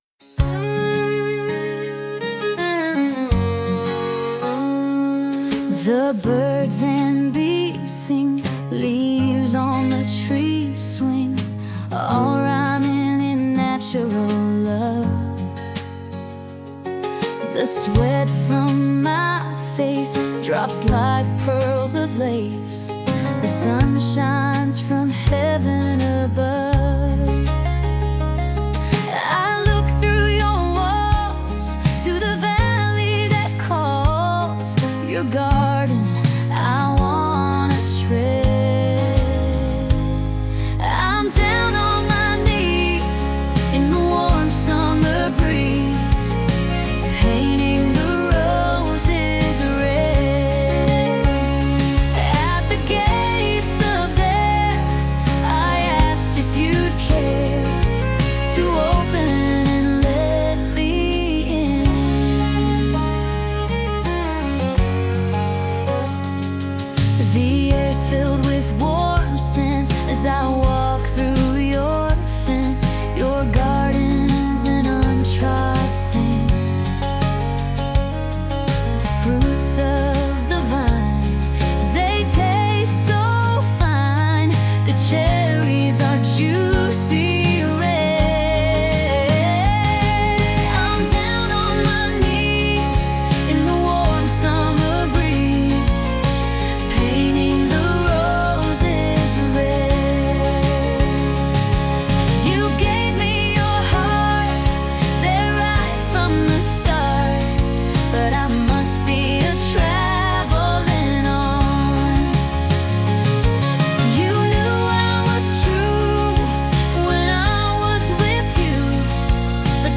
female led Country